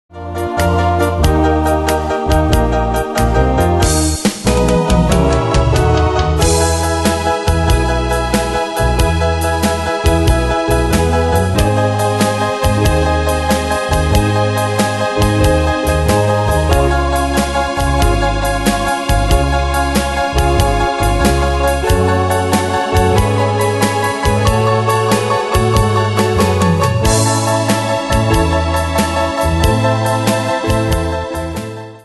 Style: Oldies Année/Year: 1962 Tempo: 93 Durée/Time: 2.17
Danse/Dance: Ballad Cat Id.
Pro Backing Tracks